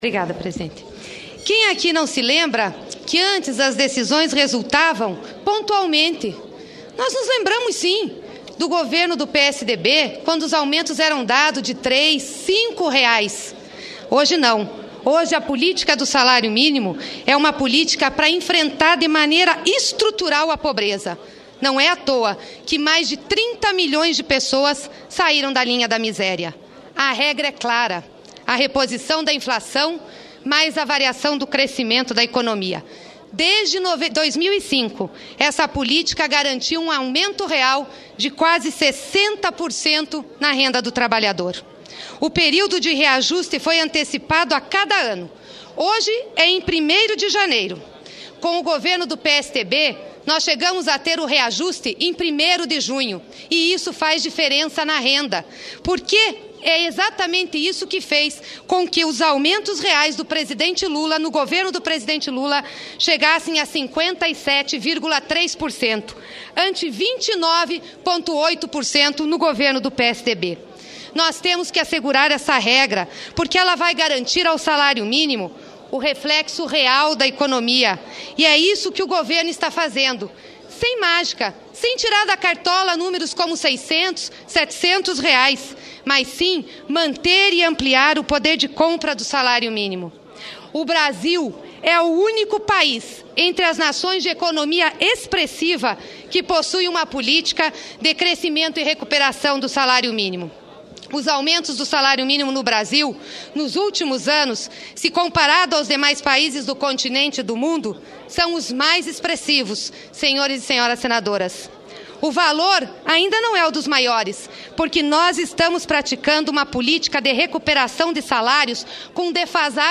Discurso da senadora Gleisi Hoffmann